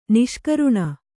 ♪ niṣkaruṇa